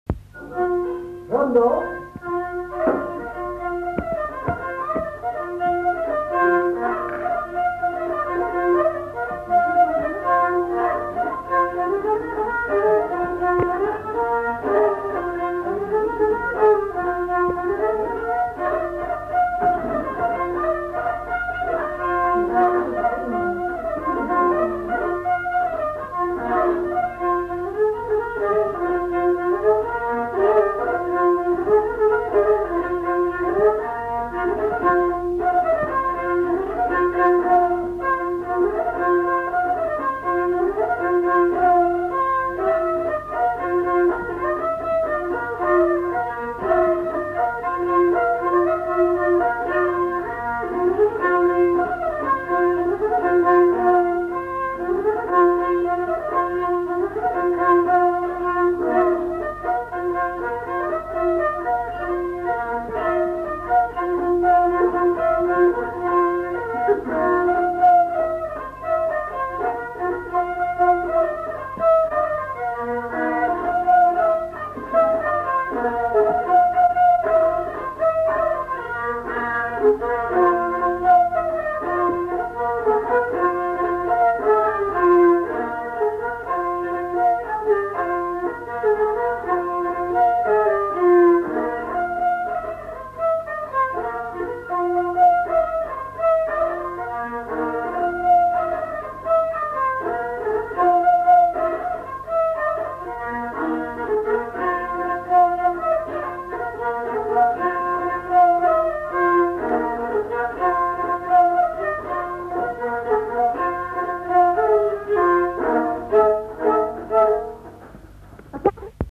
Lieu : Haut-Mauco
Genre : morceau instrumental
Instrument de musique : violon
Danse : rondeau